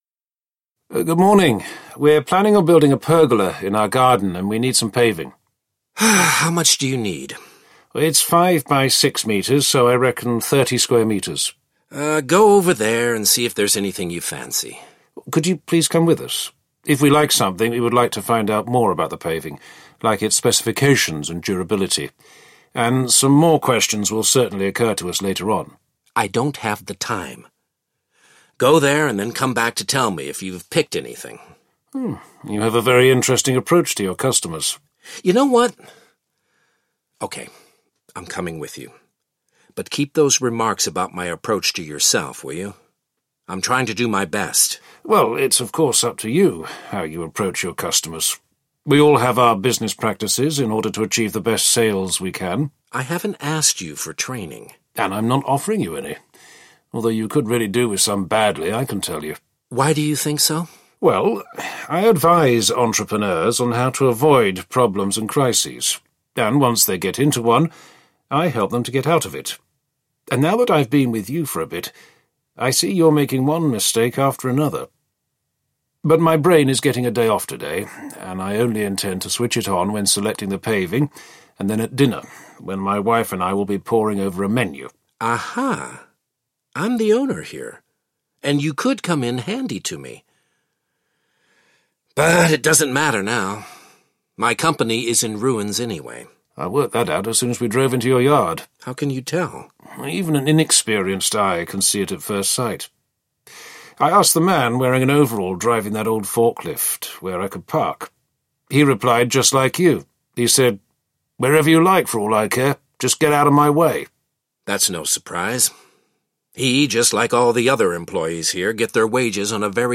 Business Risk Buster Intervenes 4 audiokniha
Ukázka z knihy